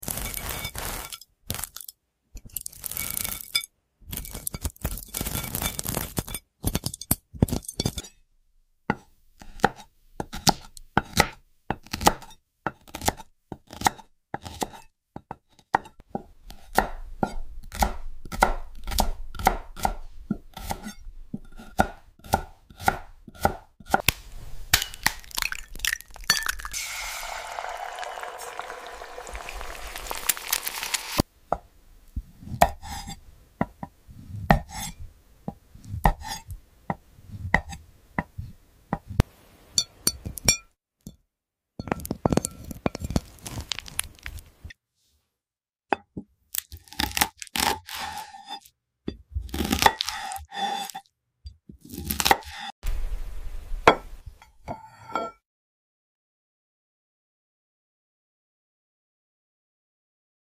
🍙✨🔪 Cutting Solid Glass Kimbap!